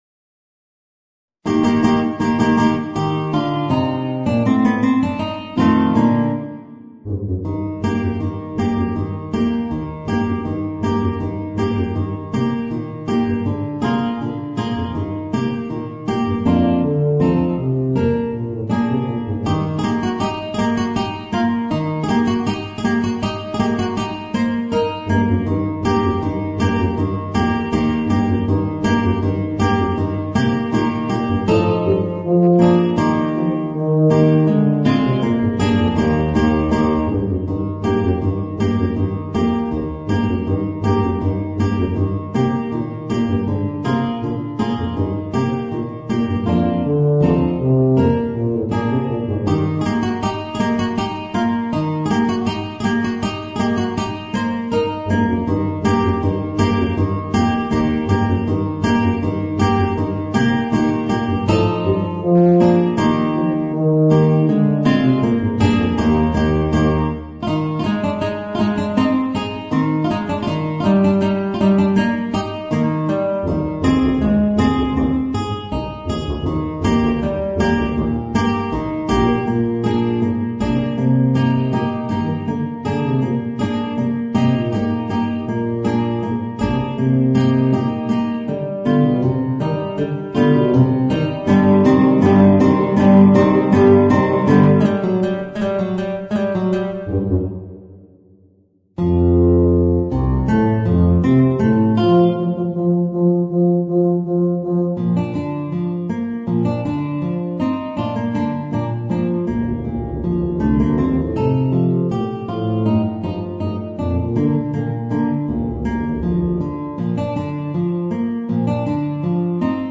Voicing: Tuba and Guitar